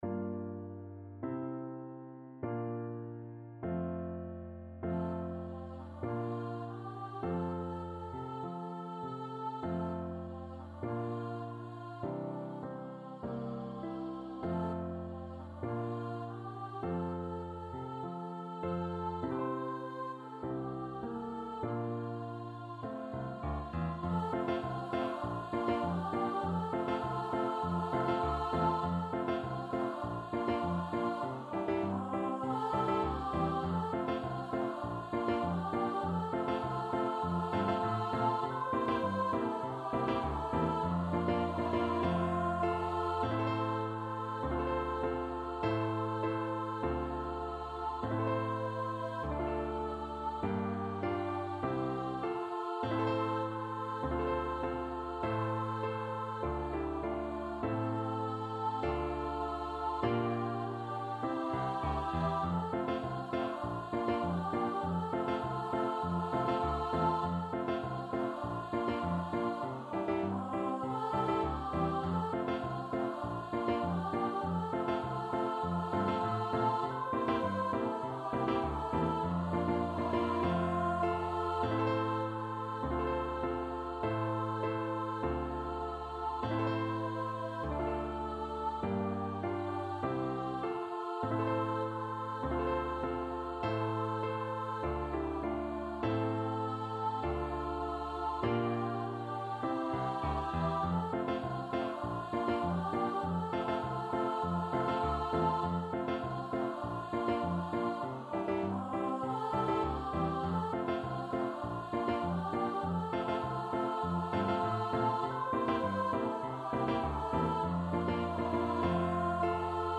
Free Sheet music for Choir (SA)
Moderato =c.100
4/4 (View more 4/4 Music)
Choir  (View more Easy Choir Music)